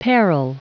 Prononciation du mot parral en anglais (fichier audio)
Prononciation du mot : parral